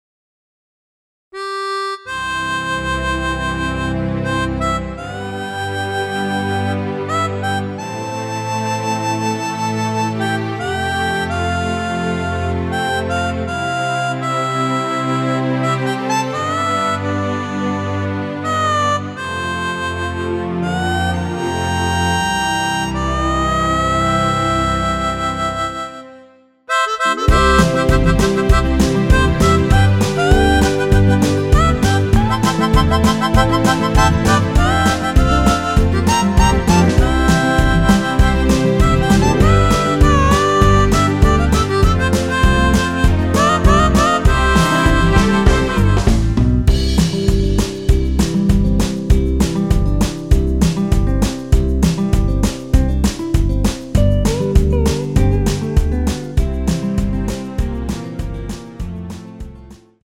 앞부분30초, 뒷부분30초씩 편집해서 올려 드리고 있습니다.
위처럼 미리듣기를 만들어서 그렇습니다.